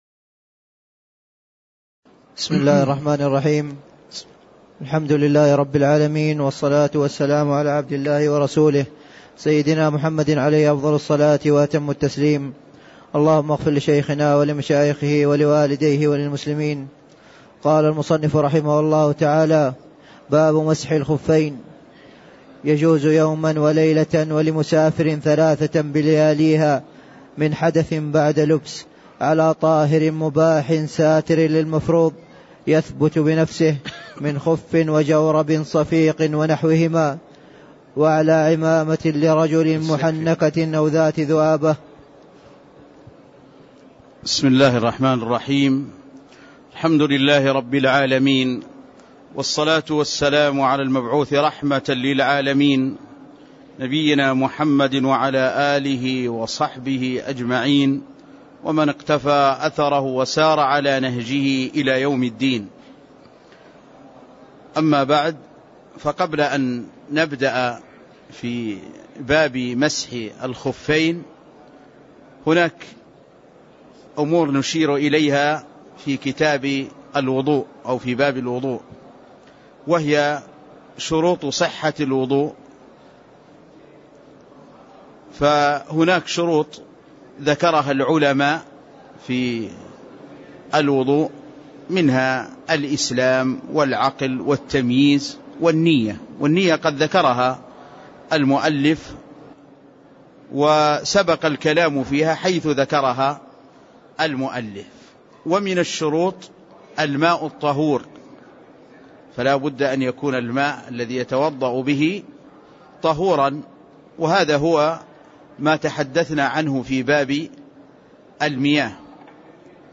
تاريخ النشر ١٥ جمادى الأولى ١٤٣٥ هـ المكان: المسجد النبوي الشيخ